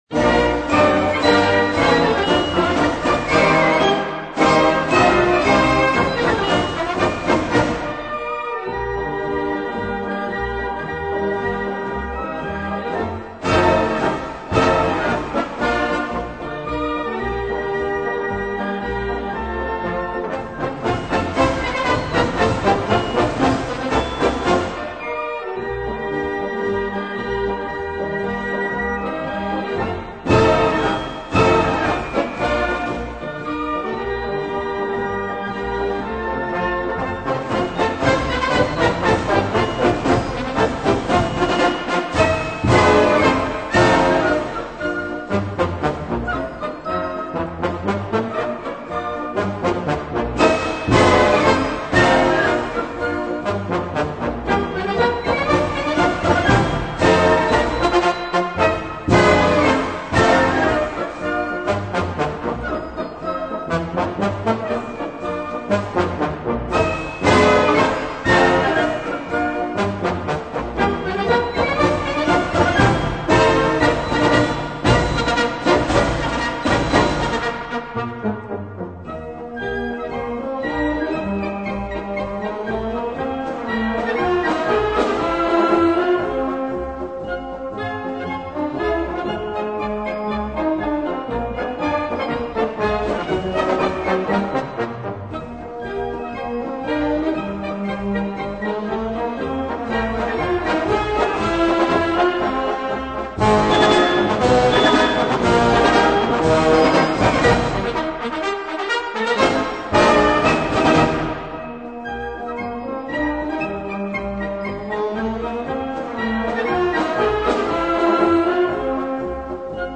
Marschmusik